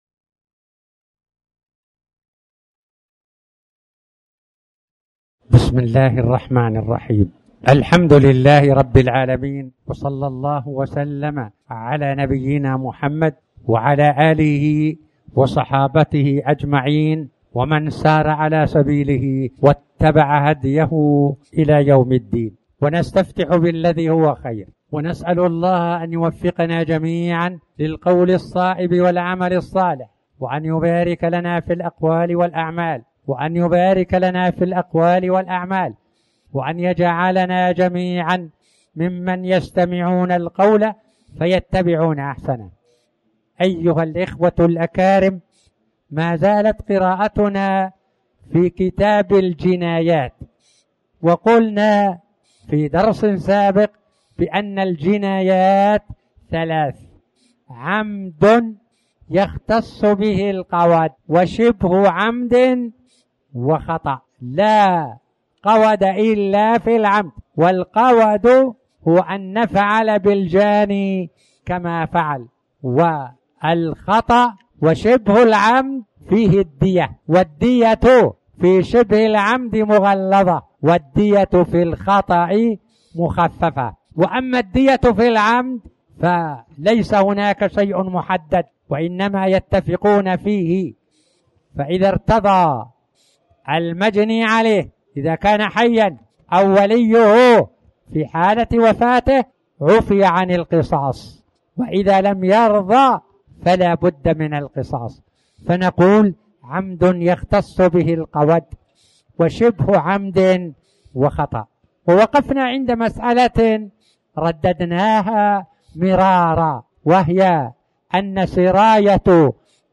تاريخ النشر ١٣ شعبان ١٤٣٩ هـ المكان: المسجد الحرام الشيخ